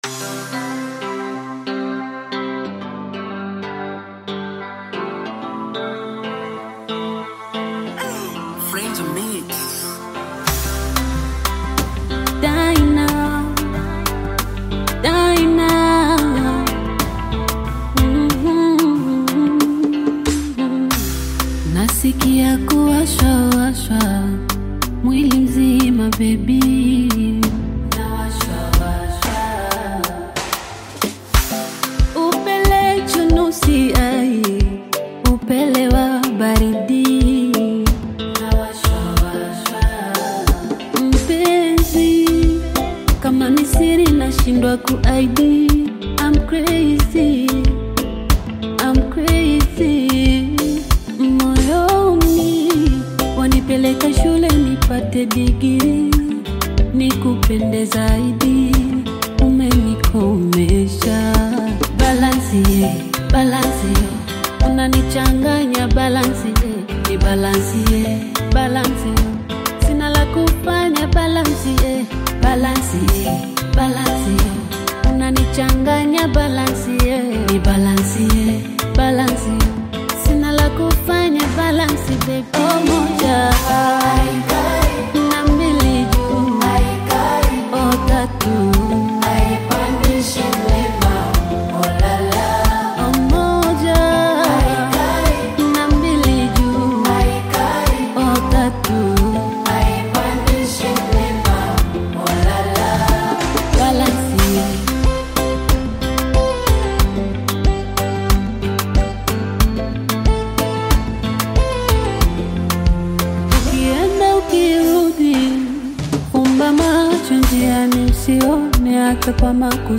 Tanzanian Bongo Flava
Bongo Flava